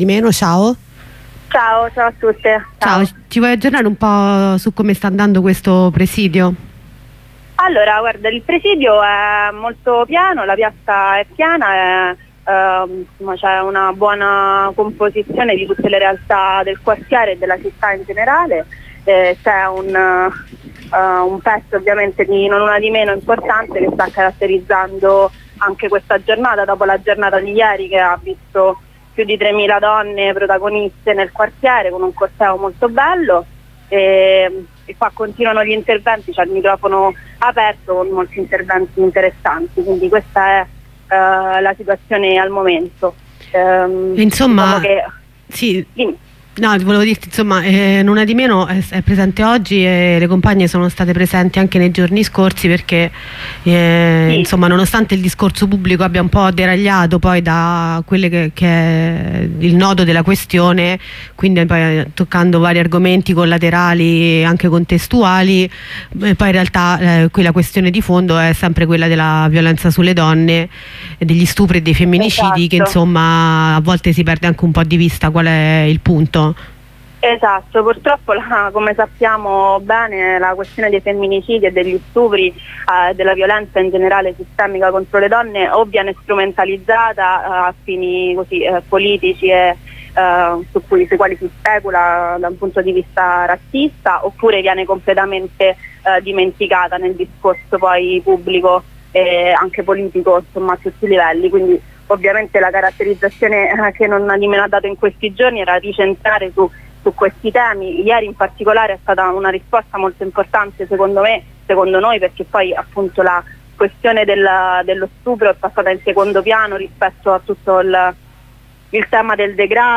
Corrispondenza con una compagna di Non Una di Meno